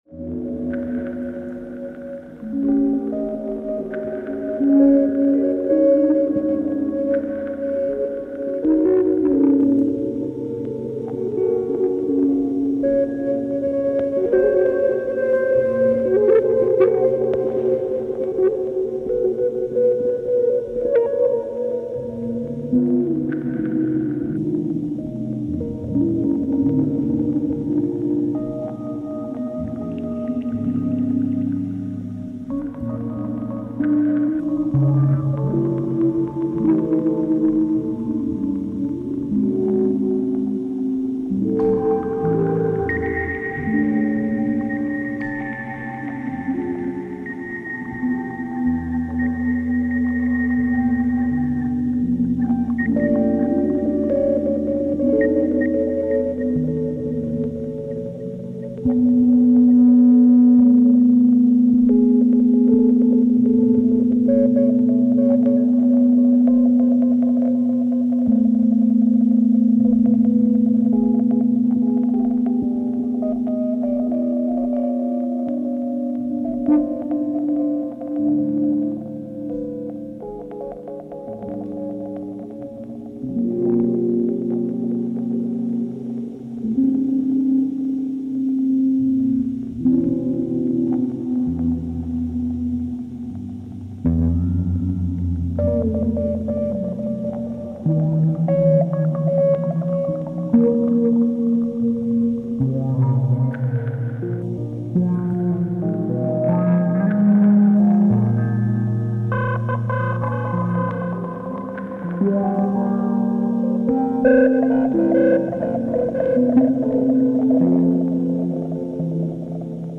fender rhodes
electric guitar
Recorded in Theatre La Balsamine, Brussels, april 2018